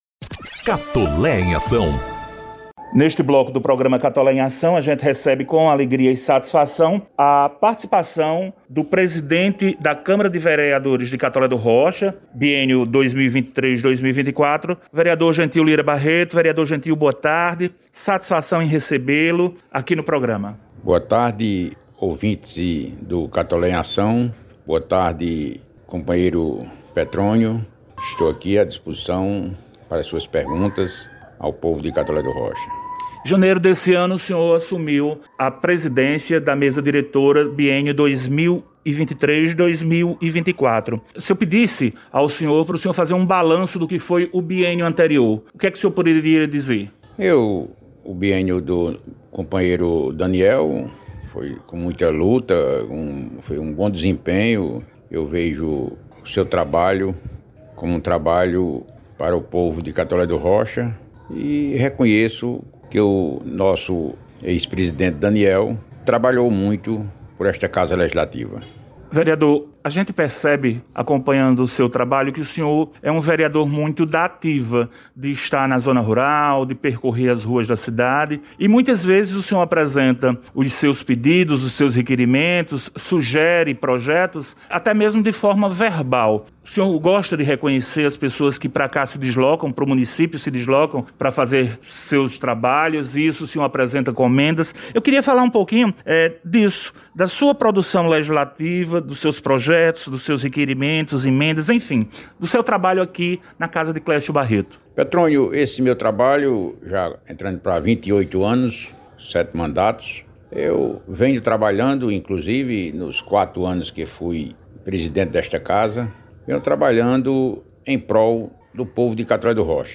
Acompanhe na íntegra a entrevista com o Presidente da Câmara Municipal Dr Gentil Barreto: